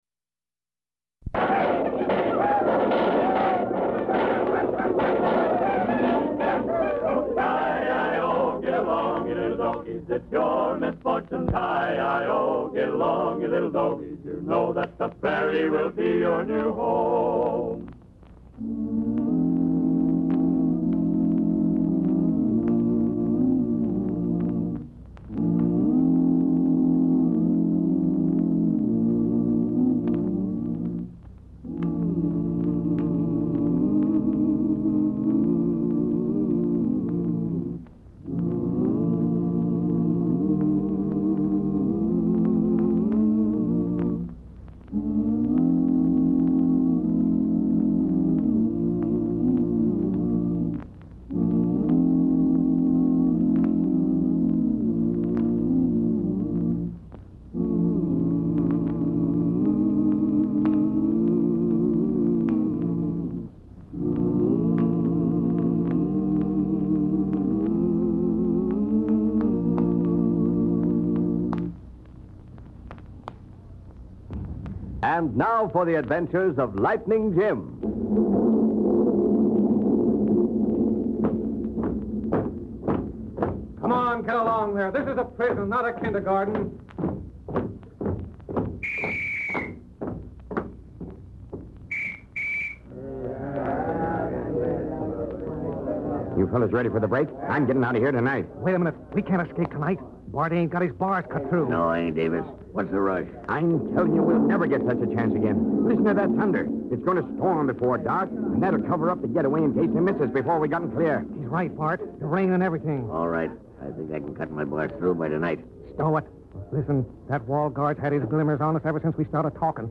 "Lightning Jim" was a popular old-time radio show that featured the adventures of U.S. Marshal Lightning Jim Whipple. - The character of Lightning Jim, along with his trusty horse Thunder and deputy Whitey Larson, became iconic figures in Western radio drama.